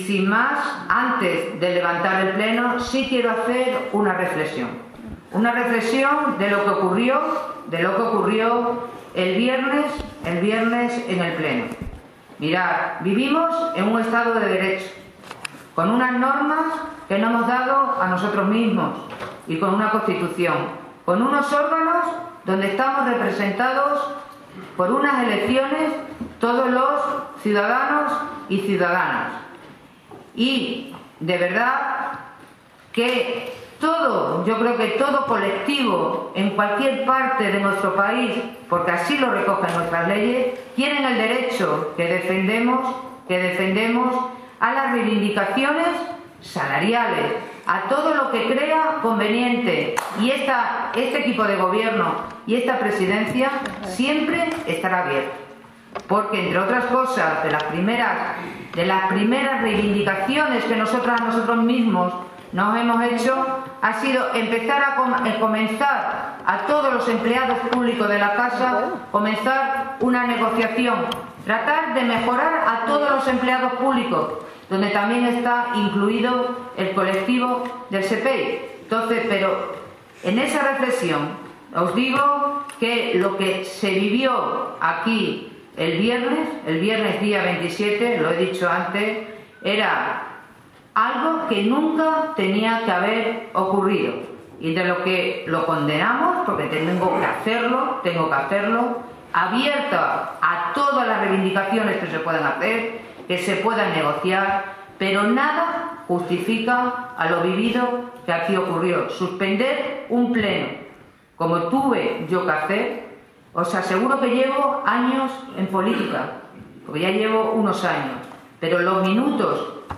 CORTES DE VOZ
Precisamente, en relación a dichos sucesos, la Presidenta de la Diputación, Charo Cordero, ha querido dejar clara su postura.